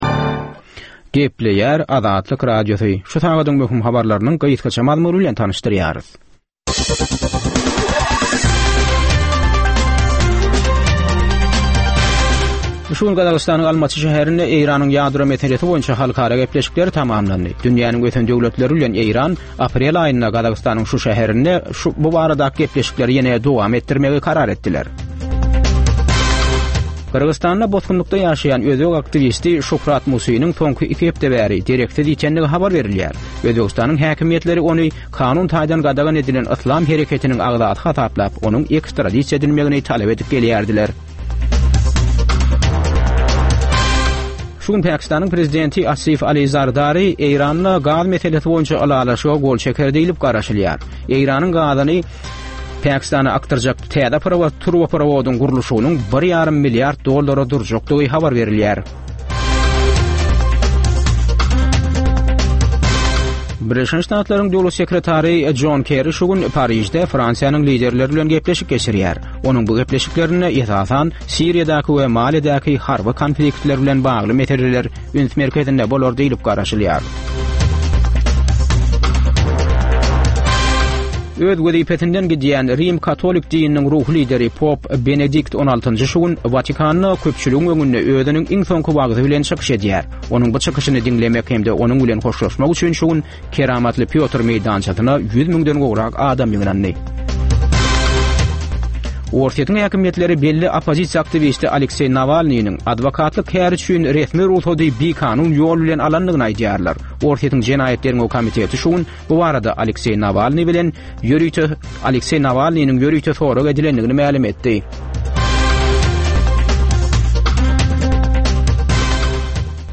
Türkmenistanly ýaşlaryň durmuş, okuw, iş meselelerini beýan etmek üçin döredilen programma. Bu programmada ýaşlary gyzyklandyrýan, ynjalykdan gaçyrýan zatlar hakda aç-açan we janly gürrüň edilýär.